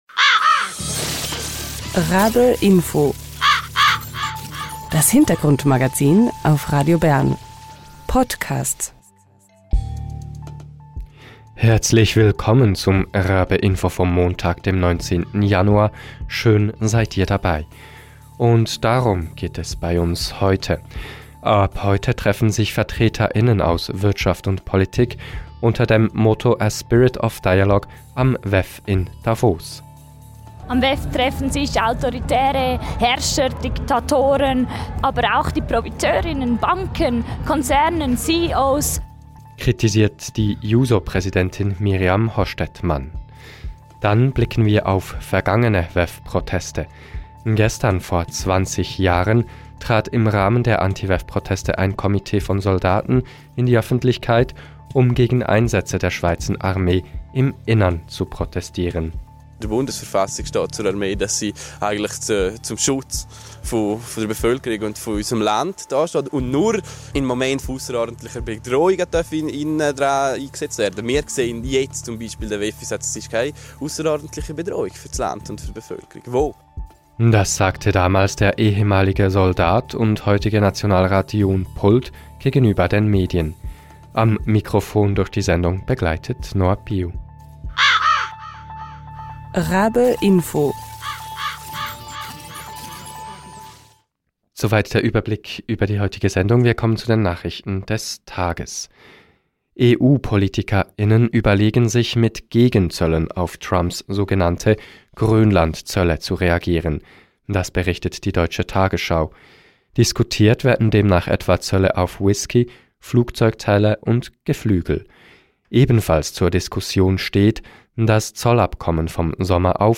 Mirjam Hostettmann, Präsidentin der JUSO Schweiz, im Interview.